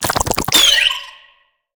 Sfx_creature_penguin_skweak_11.ogg